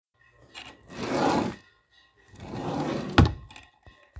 Sound Effects
Drawer
Drawer.m4a